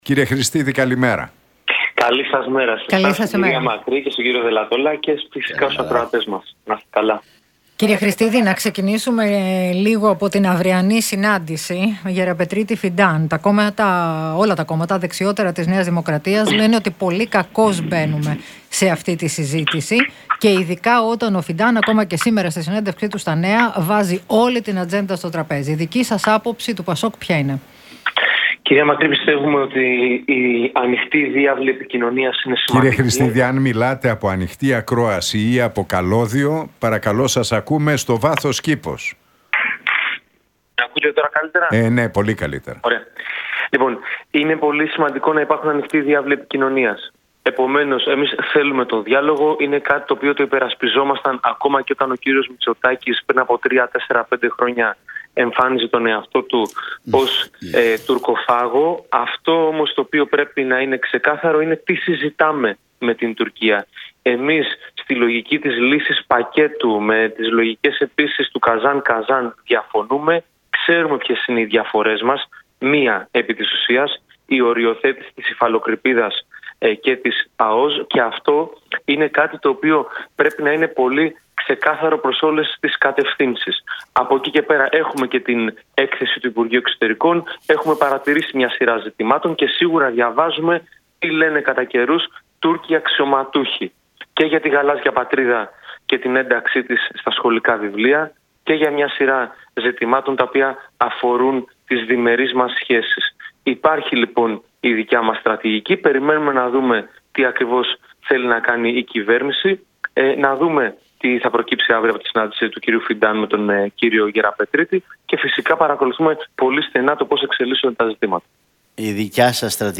Για τα ελληνοτουρκικά, την εκλογή Τραμπ και την κατάσταση της ελληνικής οικονομίας μίλησε ο βουλευτής του ΠΑΣΟΚ, Παύλος Χρηστίδης